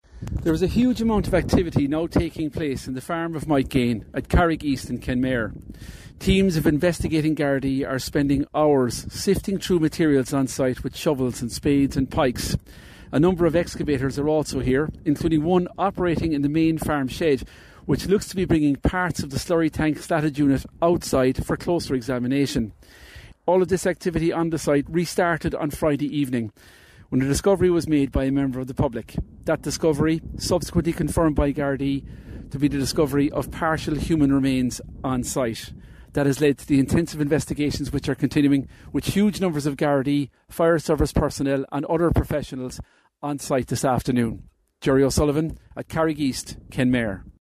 reports from Carrig East: